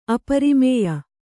♪ aparimēya